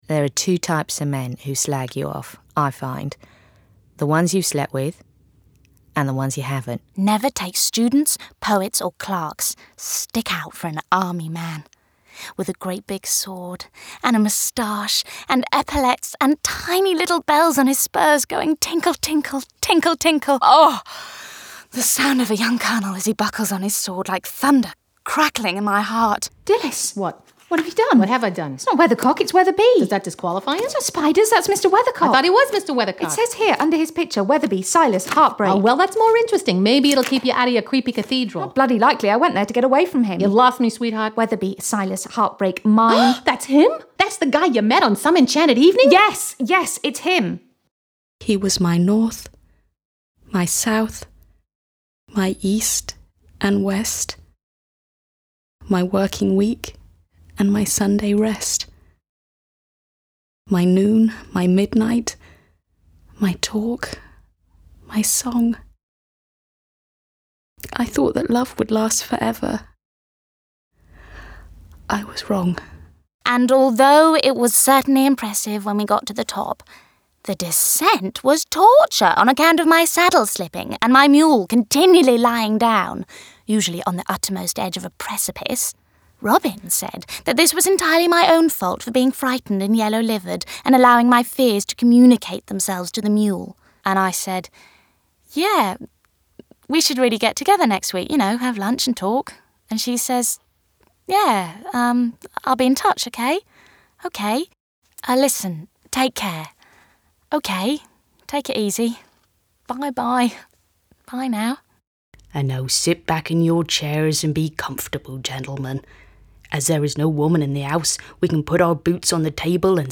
I have a naturally husky, sexy mid tone.
Drama